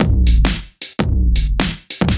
Index of /musicradar/off-the-grid-samples/110bpm
OTG_Kit 1_HeavySwing_110-C.wav